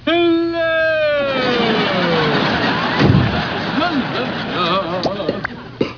Jerry-(in the voice)-